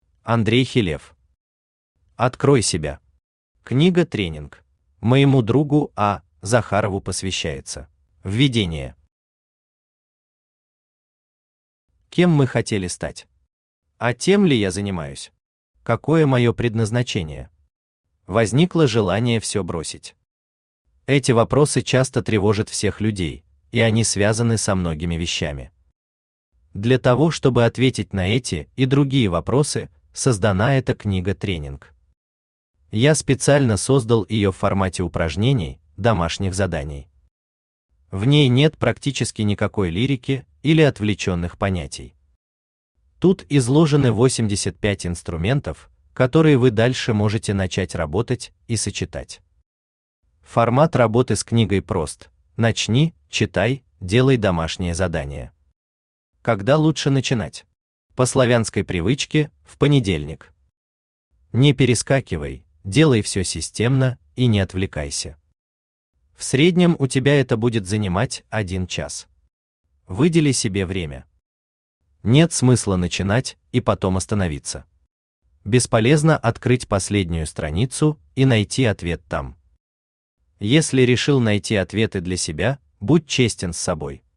Аудиокнига Открой себя. Книга-тренинг | Библиотека аудиокниг
Книга-тренинг Автор Андрей Хилев Читает аудиокнигу Авточтец ЛитРес.